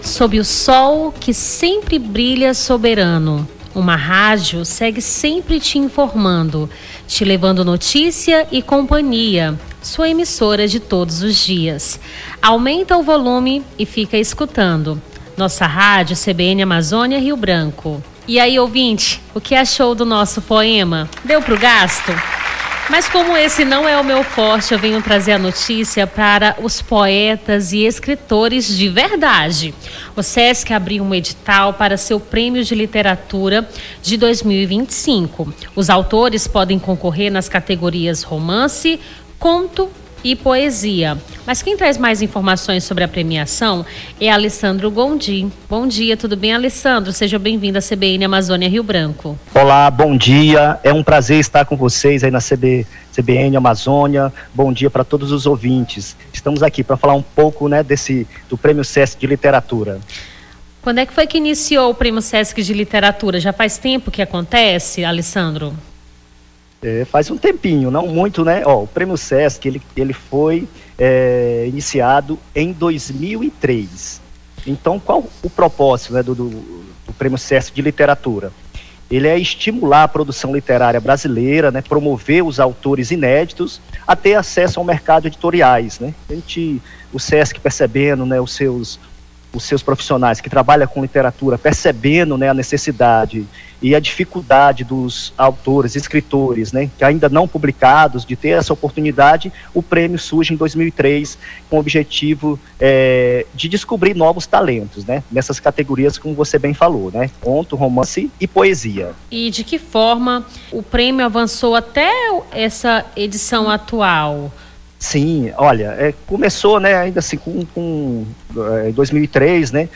Nome do Artista - CENSURA - ENTREVISTA PRÊMIO SESC DE LITERATURA (24-02-25).mp3